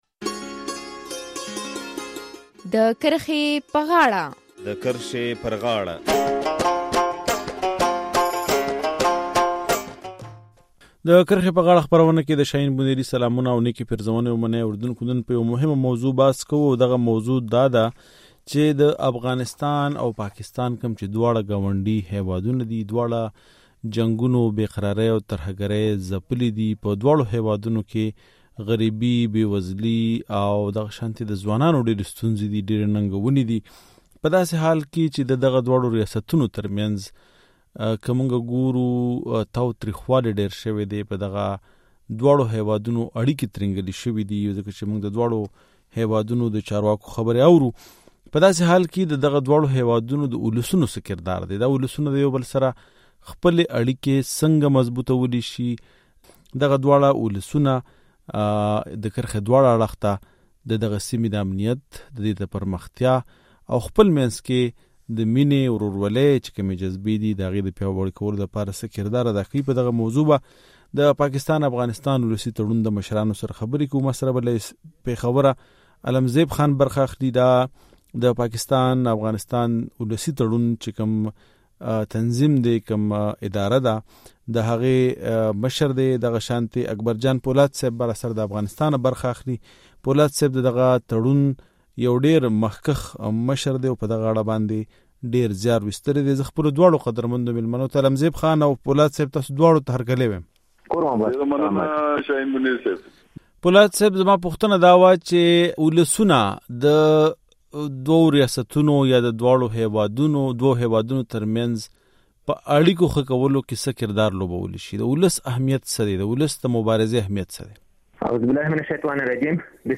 د افغانستان او پاکستان تر منځ د سیاستونه او يو پر بل د تورونه پورې کولو سربېره د دواړو هيوادونو او په ځانګړې توګه د دواړو خواوو پښتانه له یو بل سره د اړیکو ښه کولو، امنیت په ځای کولو، او خپلې ژبې او کلتور ته په وده ورکولو کې څه رول لوبولی شي؟ نن د کرښې په غاړه کې د پاکستان افغانستان ولسي تړون د مشرانو سره پر دغه موضوع بحث لرو